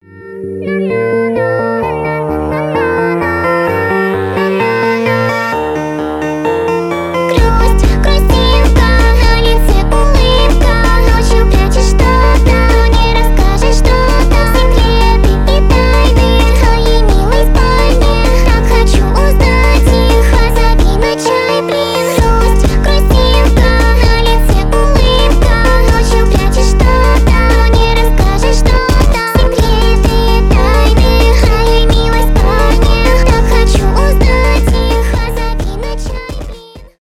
поп , hyperpop